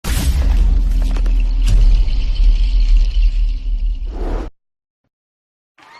Crowd Cheer
# crowd # cheer # applause About this sound Crowd Cheer is a free sfx sound effect available for download in MP3 format.
015_crowd_cheer.mp3